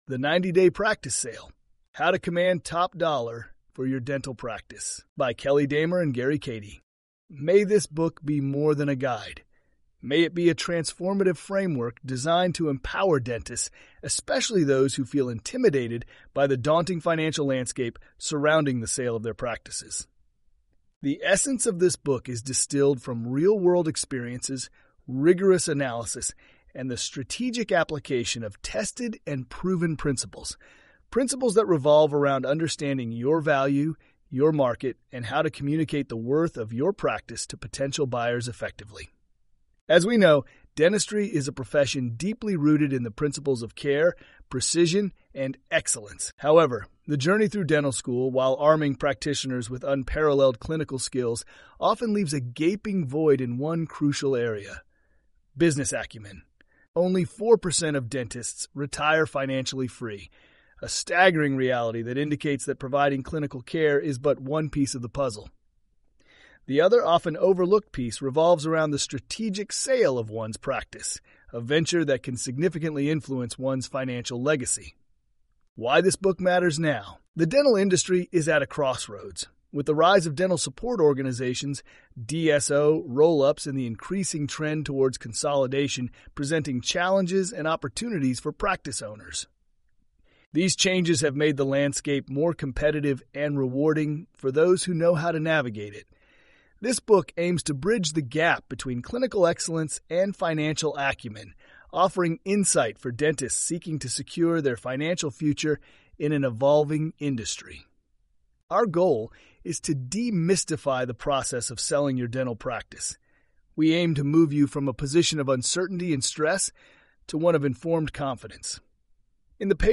90-Day Practice Sale_Audiobook.mp3